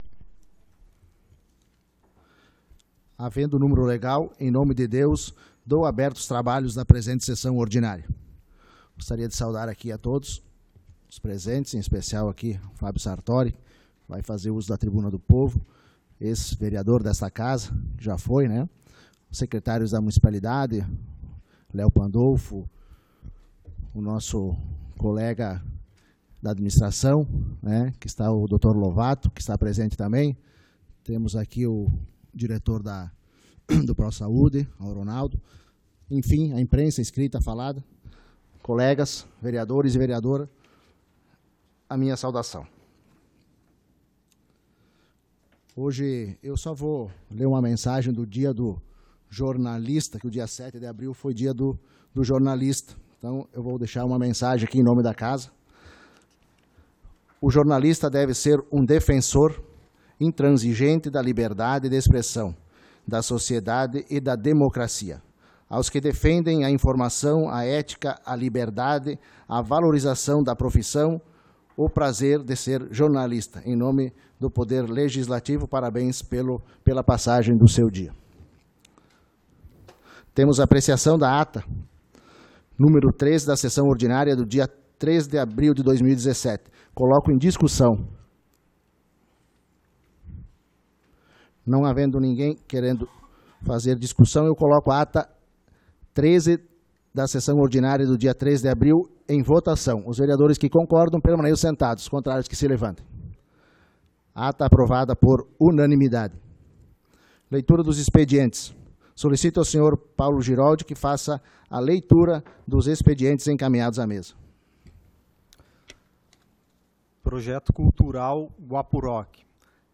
Sessão Ordinária do dia 10 de Abril de 2017